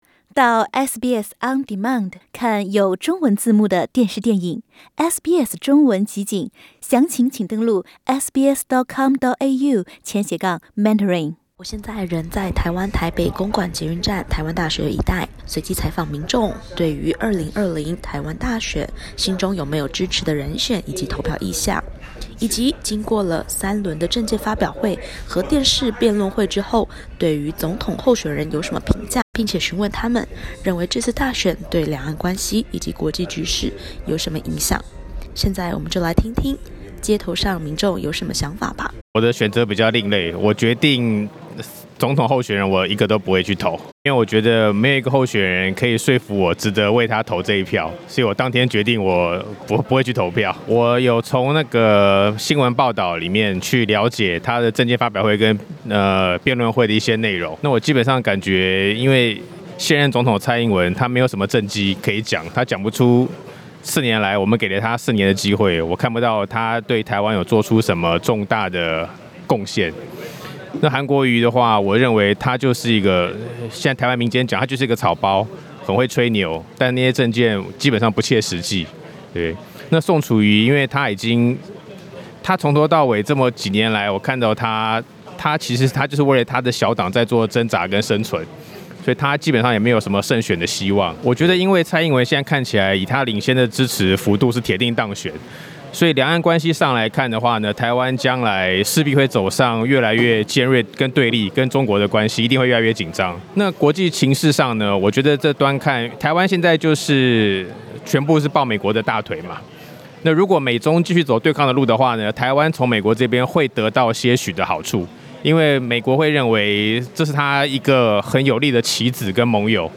2020台湾选举倒数十天，记者实地走访台北街头，倾听民众多元的声音：韩粉、英粉、郭粉，甚至也有铁下心不去投票的。不过，他们都很关注选举对两岸关系的影响。